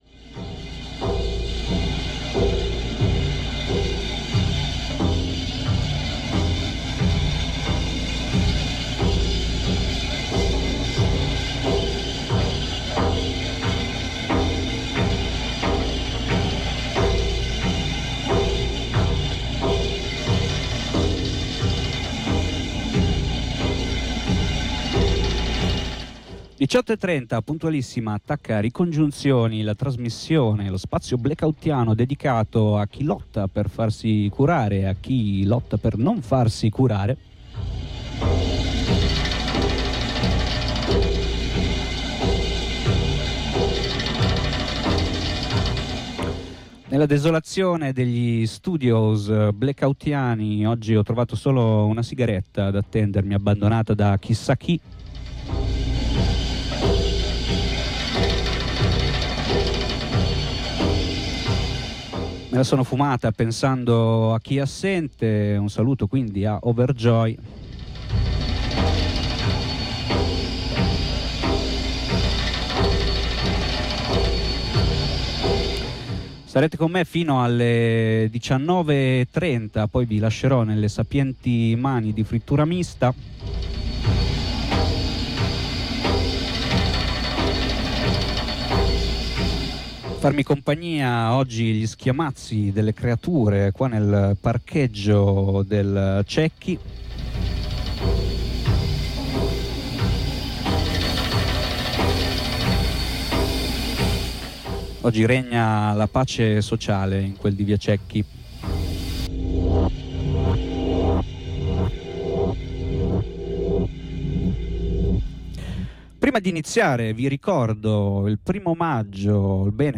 Che genere di relazioni umane rende possibile l'esistenza della psichiatria, e di conseguenza che energie si liberano quando si rinuncia ad essa per gestire crisi e conflitti? Ne abbiamo parlato attraverso l'intervista a due compagne anarchiche che raccontano la loro esperienza di diserzione dalla salute mentale come professioniste, pazienti ed intorno complice.